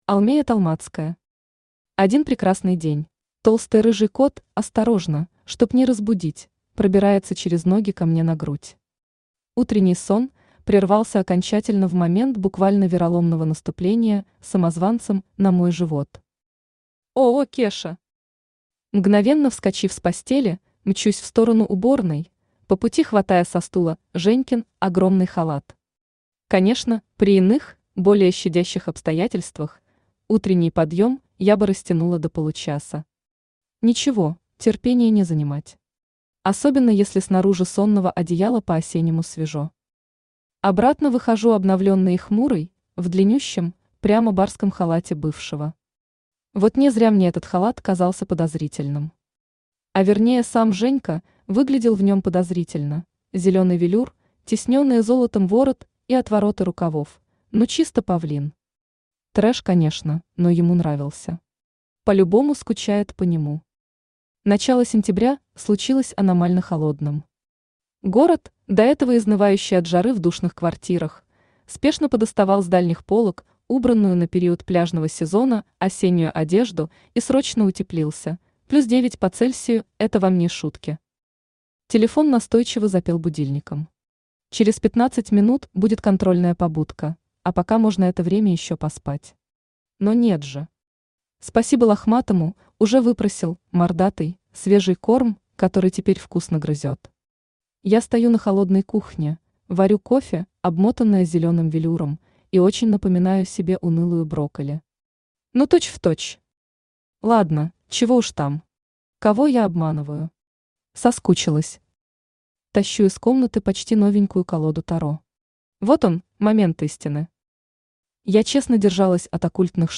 Аудиокнига Один прекрасный день | Библиотека аудиокниг
Aудиокнига Один прекрасный день Автор Алмея Толмацкая Читает аудиокнигу Авточтец ЛитРес.